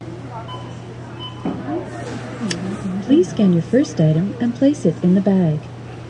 杂货店环境 " 杂货店环境 1
描述：一般杂货店的氛围。
Tag: 收银机 杂货店 收据打印机声音